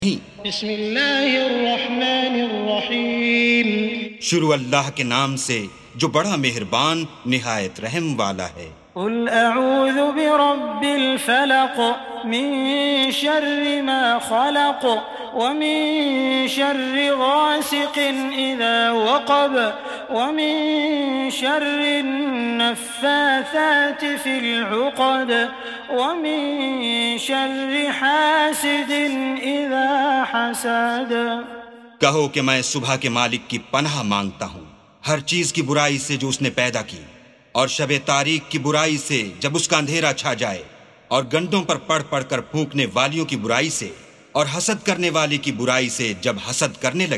سُورَةُ الفَلَقِ بصوت الشيخ السديس والشريم مترجم إلى الاردو